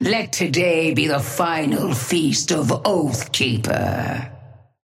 Sapphire Flame voice line - Let today be the final feast of Oathkeeper.
Patron_female_ally_ghost_oathkeeper_5i_start_02.mp3